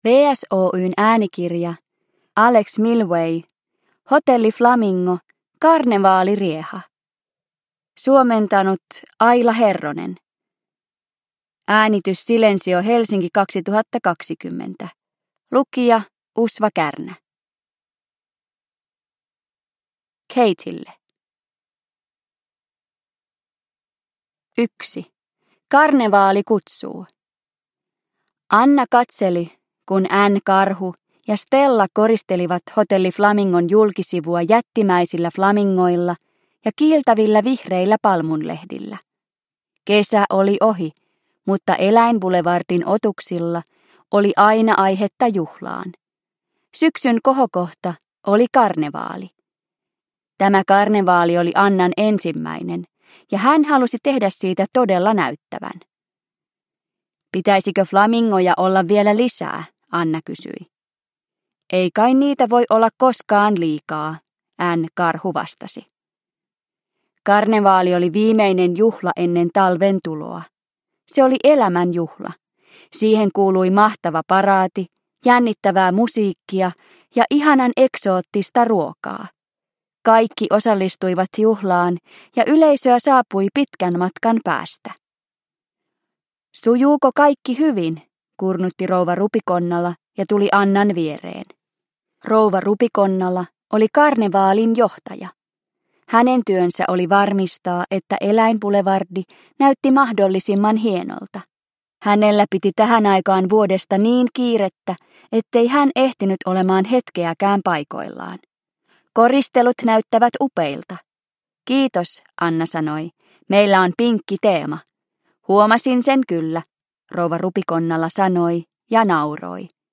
Hotelli Flamingo: Karnevaalirieha – Ljudbok – Laddas ner